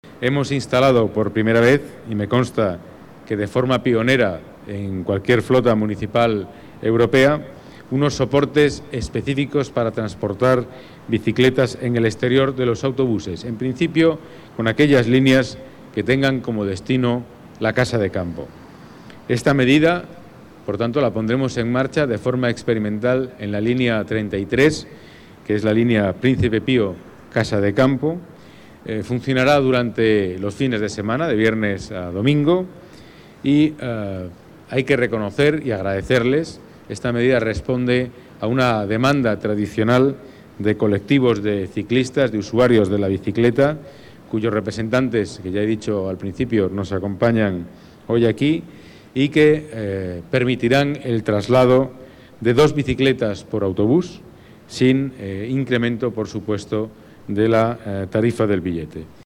Pedro Calvo, delegado de Seguridad y Movilidad, habla sobre el Manual del Usuario de la EMT Declaraciones de Pedro Calvo, delegado de Seguridad y Movilidad, sobre el soporte para el traslado de bicicletas a la Casa de Campo